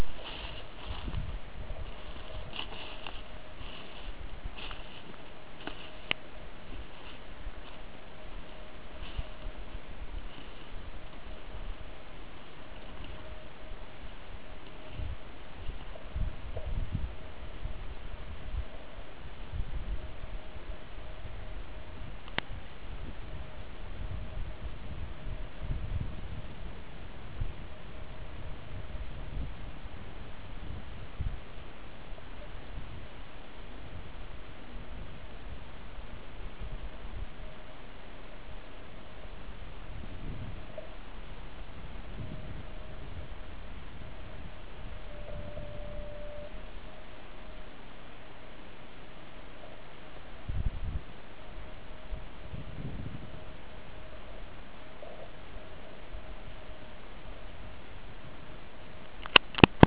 Přesto jsem si doma nechal aktivní maják se 100mW. K mému velikému překvapení jsem ho na vzdálenost 37km bezpečně slyšel.
Maják (*.wav 250KB)